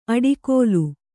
♪ aḍikōlu